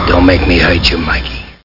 Amiga 8-bit Sampled Voice
1 channel
threat.mp3